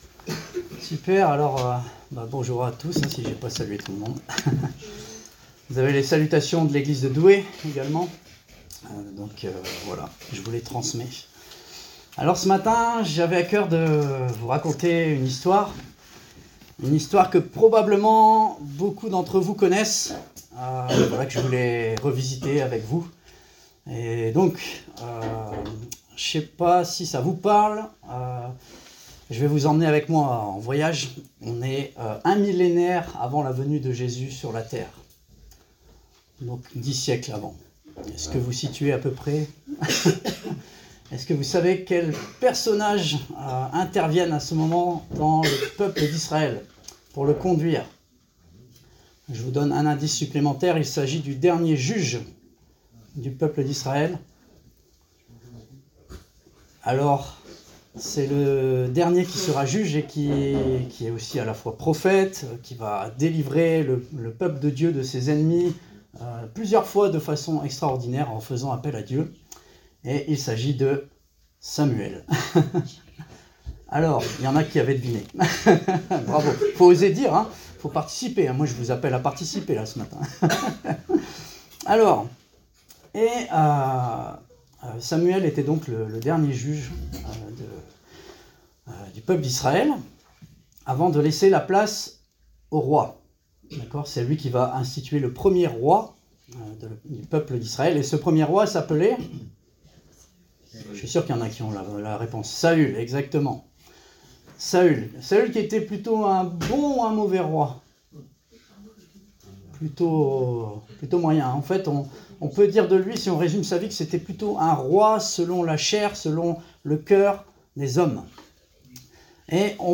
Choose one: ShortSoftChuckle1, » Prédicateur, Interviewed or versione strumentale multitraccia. » Prédicateur